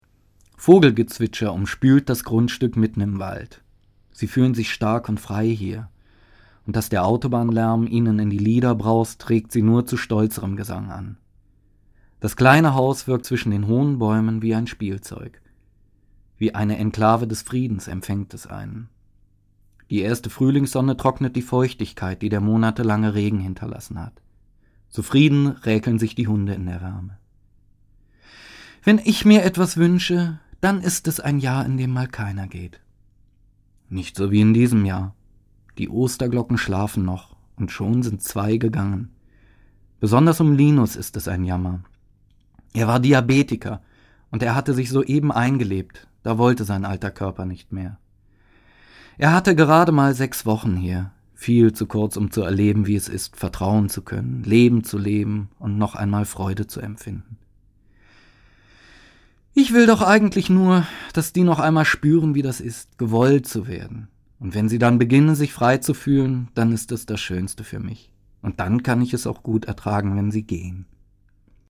Das Hörbuch für alle, die Hunde lieben.